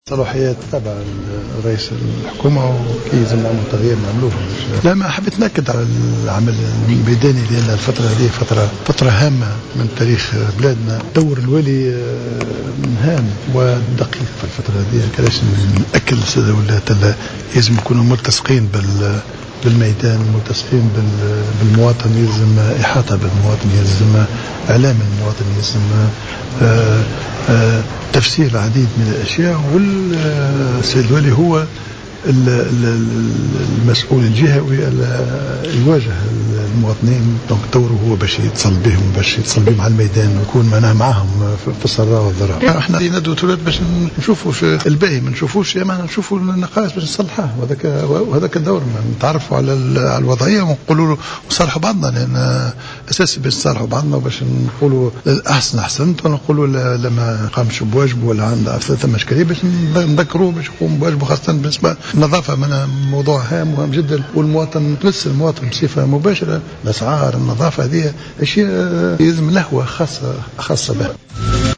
Le chef du gouvernement Habib Essid a insisté ce samedi 23 mai 2015, lors de la séance d’ouverture de la conférence des gouverneurs, sur l’importance du rôle des gouverneurs dans cette phase délicate.